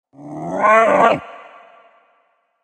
Вы можете скачать или слушать онлайн тревожные и необычные аудиоэффекты в формате mp3.